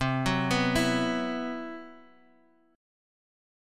Esus2/C chord